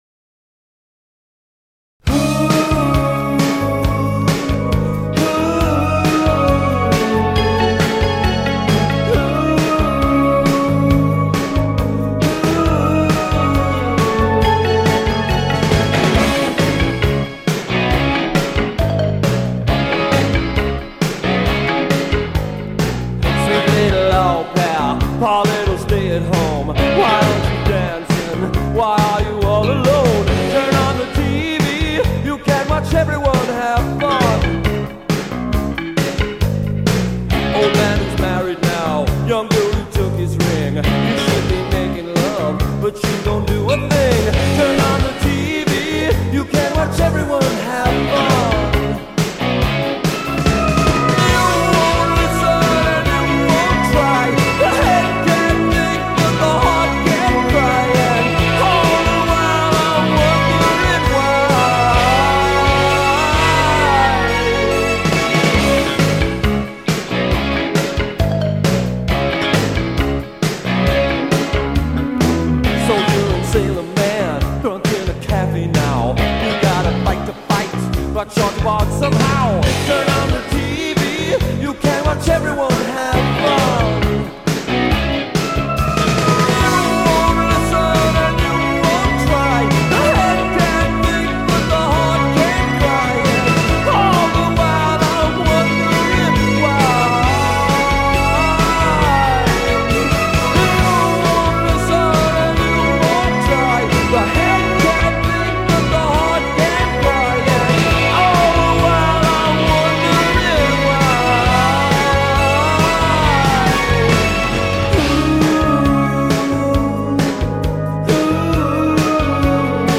keyboards/vocals
lead vocals/guitar
bass
drums